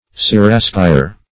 Siraskier \Si*ras"kier\, n.
siraskier.mp3